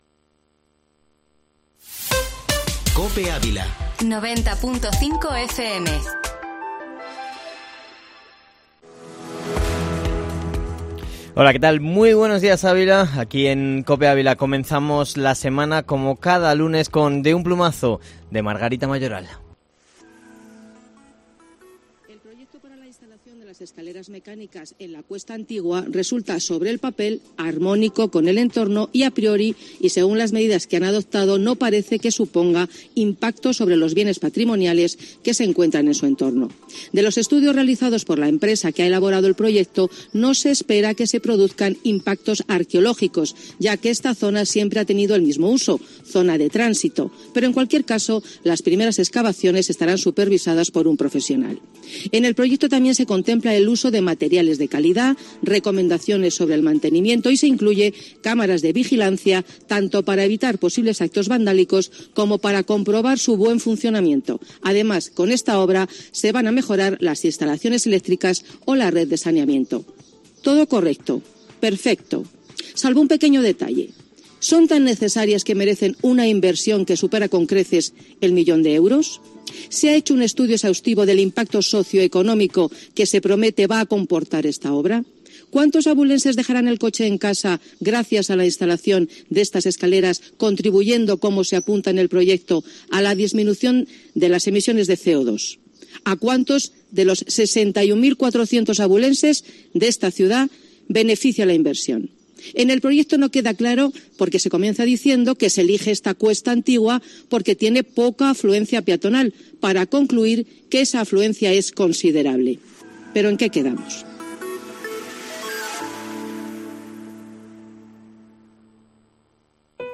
Informativo Matinal Herrera en COPE Ávila -20-junio